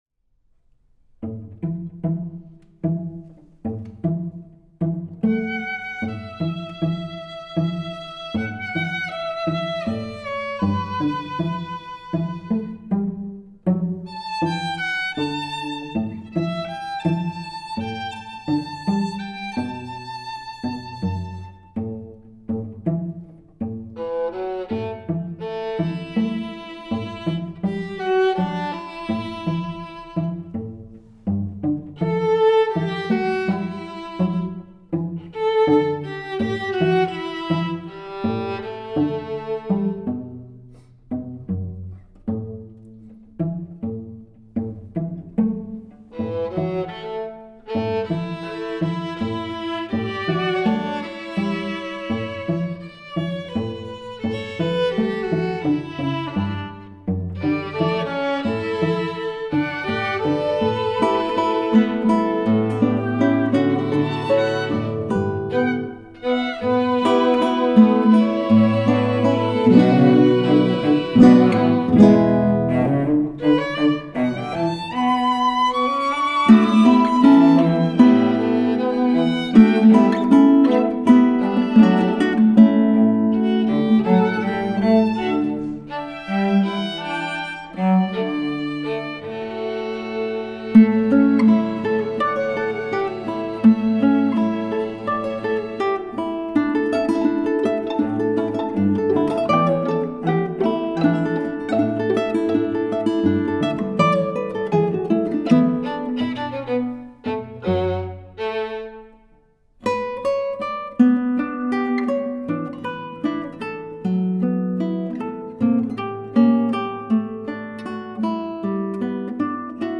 for guitar and string quartet